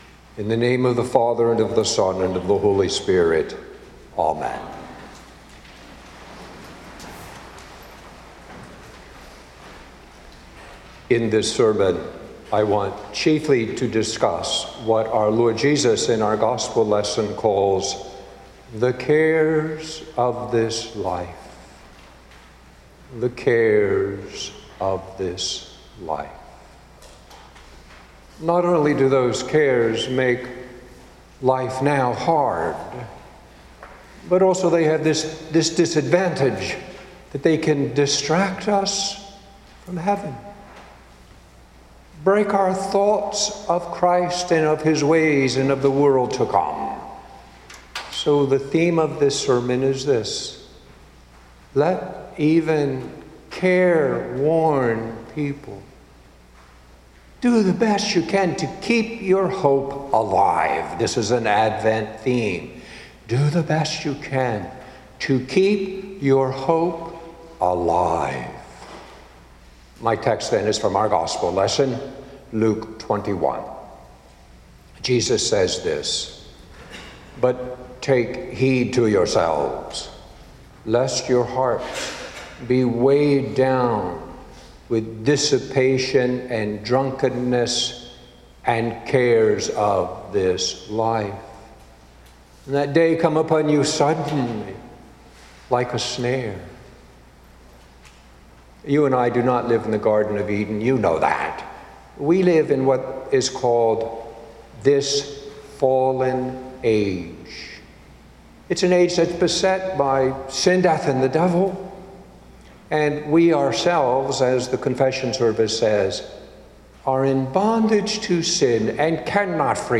sermon.mp3